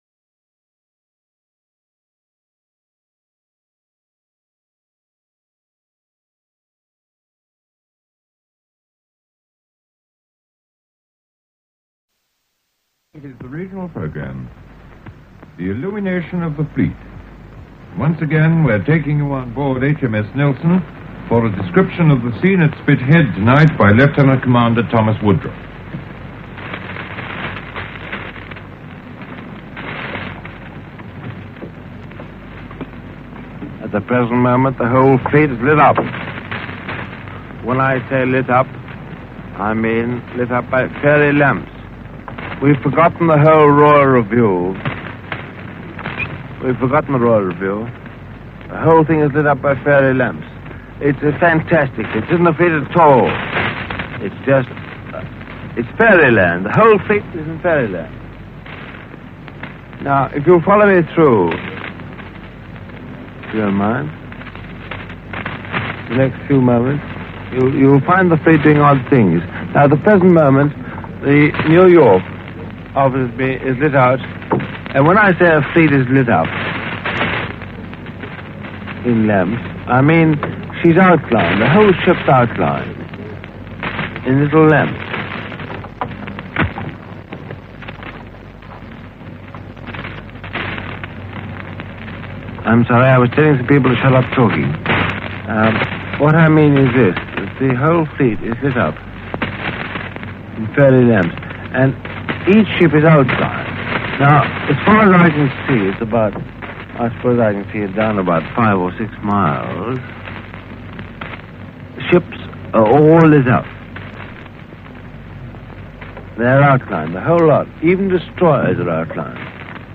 Tired and Emotional – the BBC’s top broadcaster of the pre-war era produces a commentary for the ages.
In 1937, he was assigned to cover the Coronation Review of the Fleet at Spithead – the sort of thing that passed as radio entertainment back then.
The resulting four and a half minute broadcast is quite the experience, as Woodrooffe alternatively rambles, mumbles and shouts excitedly in the manner that associates of drunks will recognise only too well. Much of his commentary seems to consist of saying that the fleet is ‘lit up’ – much like himself, you might think – by ‘fairy lights’, something that seems to both confuse and startle him.
He ends the commentary in an almost philosophical tone: